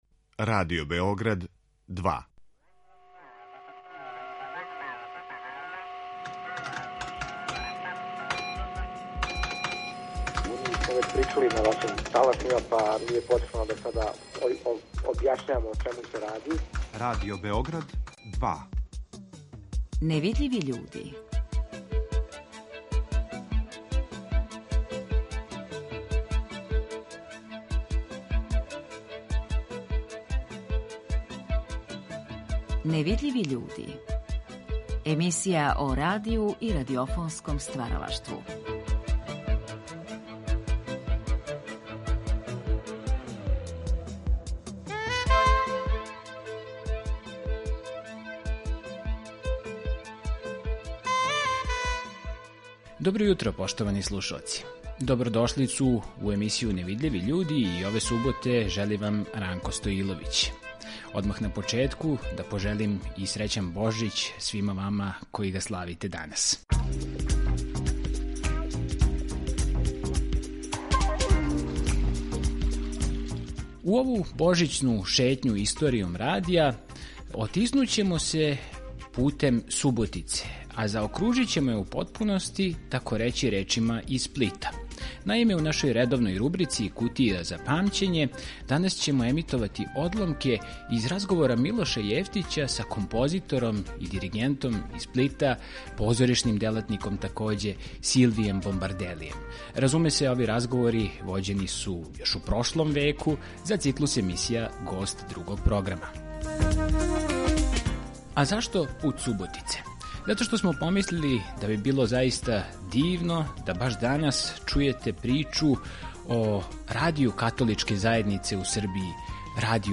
Емисија о радију и радиофонском стваралаштву